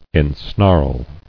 [en·snarl]